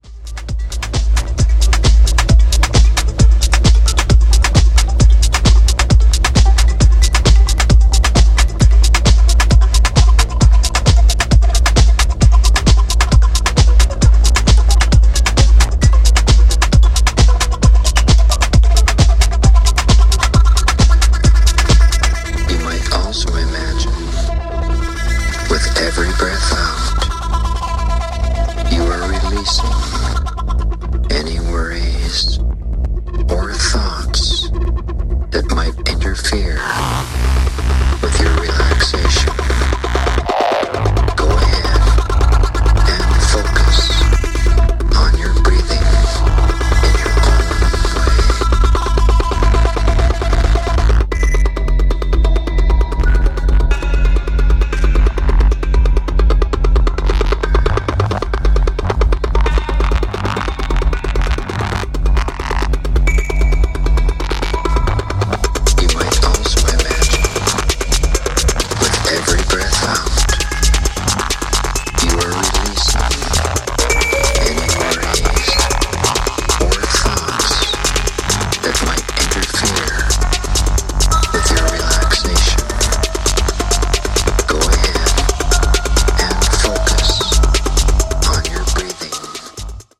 a pulsing techno beast